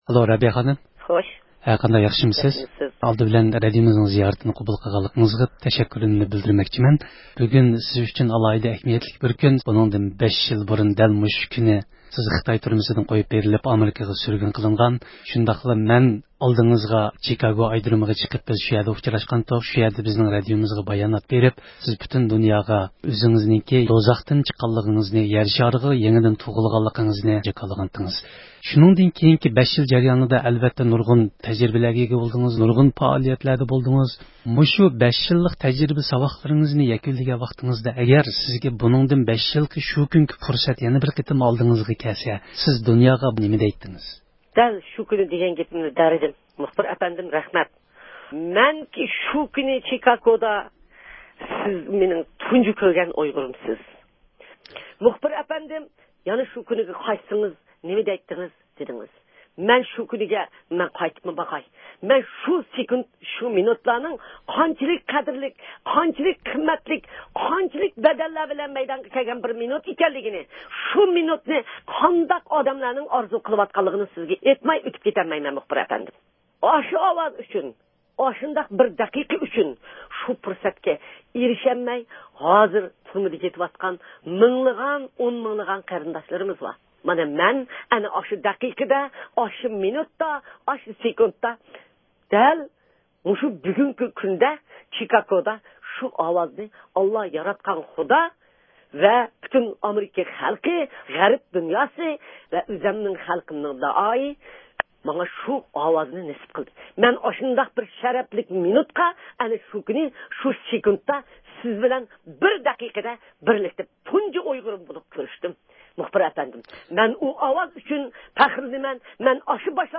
رابىيە قادىر خانىمنىڭ ئامېرىكىغا كەلگەنلىكىنىڭ 5 يىللىقى مۇناسىۋىتى بىلەن سۆھبەت – ئۇيغۇر مىللى ھەركىتى